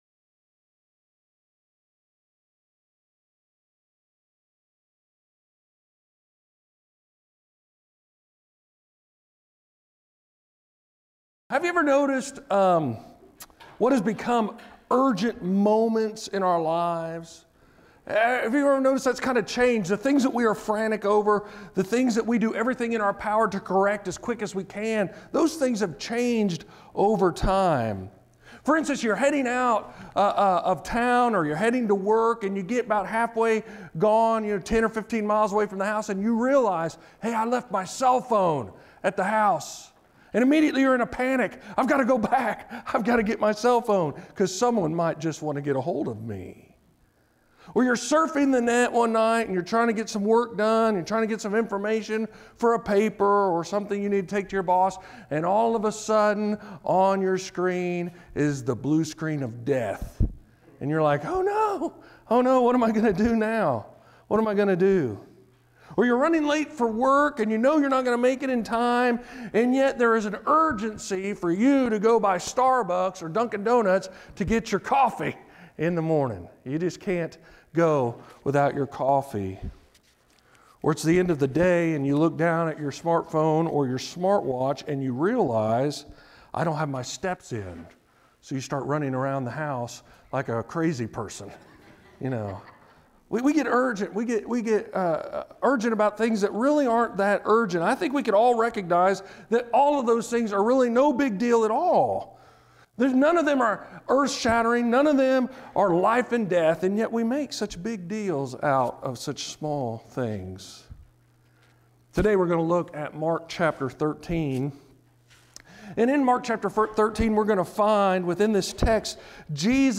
29:47 Sermons in this series Do You Believe?